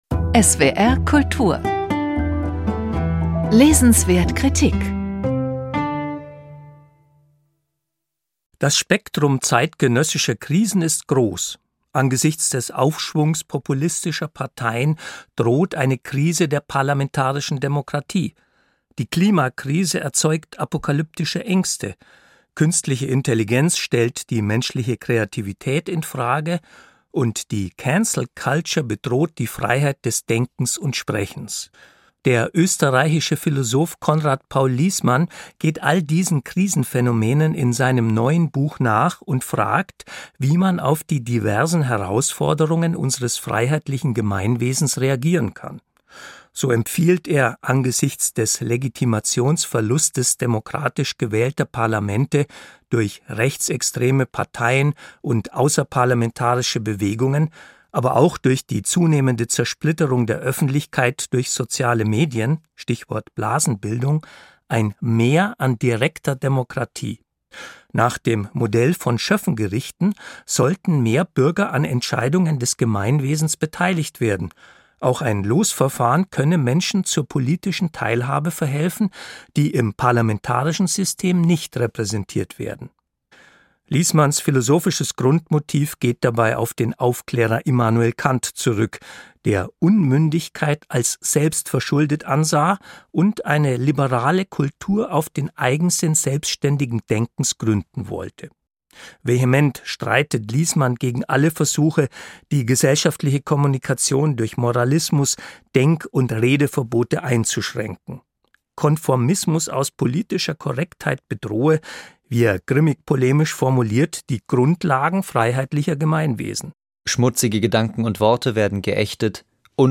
Rezension von